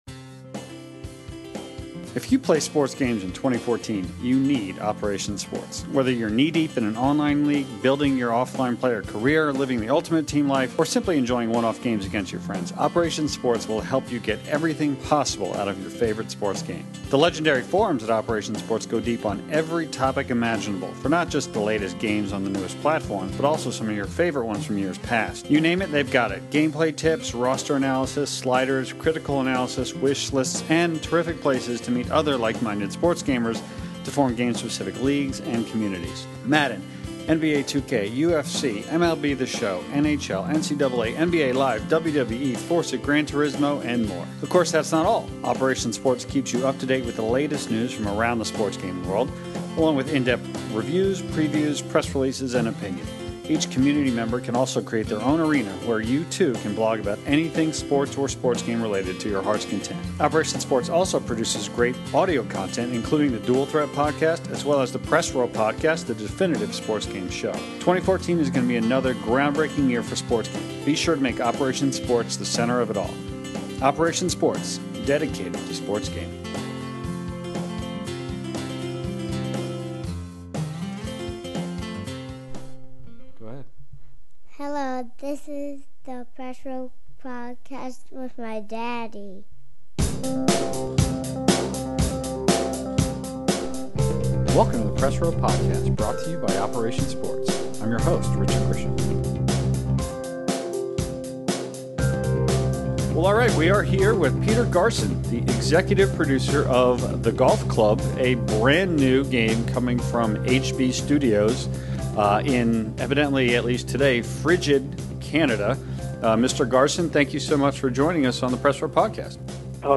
one-on-one interview